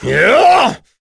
Gau-Vox_Attack5.wav